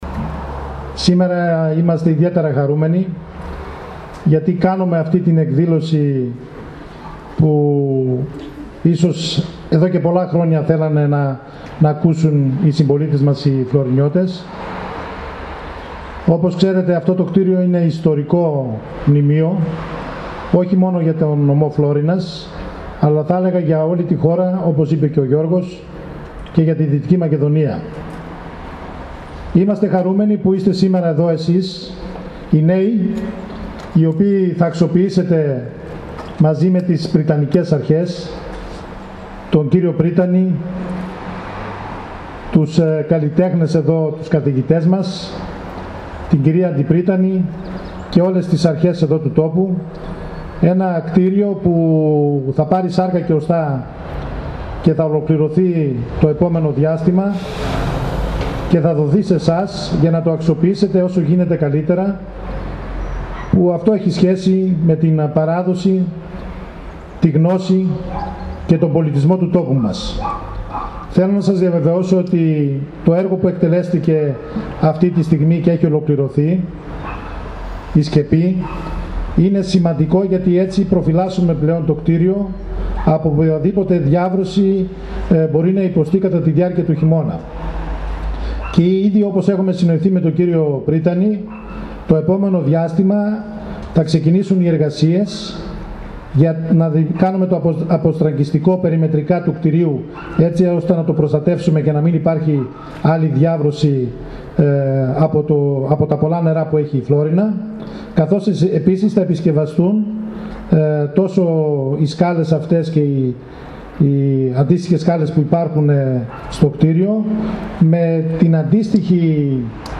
Με μια λιτή εκδήλωση γεμάτη μουσική και χαμόγελα υποδέχθηκε τους φοιτητές της Σχολής Καλών τεχνών Φλώρινας του Πανεπιστημίου Δυτικής Μακεδονίας η Περιφερειακή Ενότητα Φλώρινας στο υπό ανακαίνιση ιστορικό κτίριο της Αγίας Όλγας.